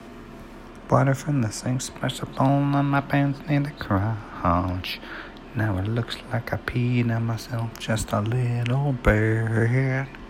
INTRO guitar